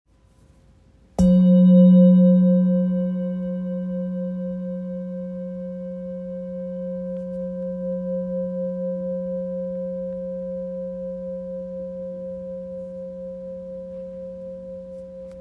This enhanced oscillation makes it perfect for bodywork, as its deep, resonant tones penetrate the body, promoting relaxation, balancing energy centers, and restoring harmony on a cellular level.
Its rich tones and sustained vibrations make it a versatile tool for meditation, energy healing, and therapeutic use.